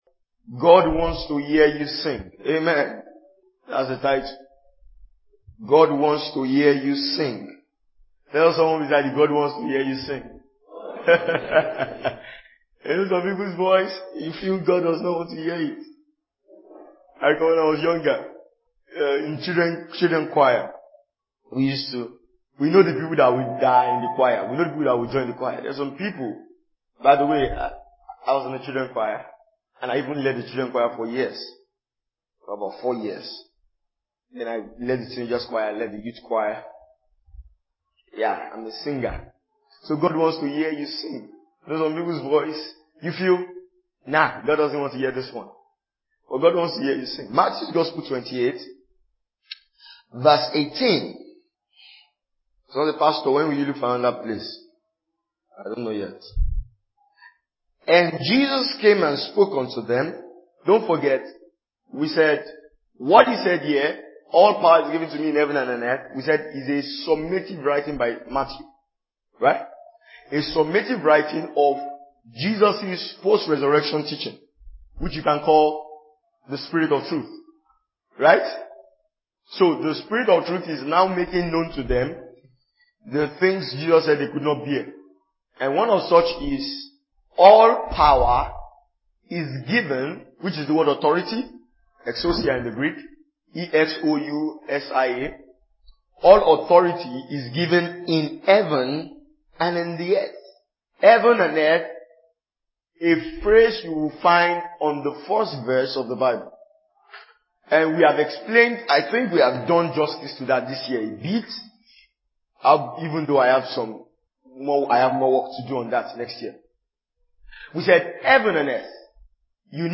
2021 Glorious House Church Teachings.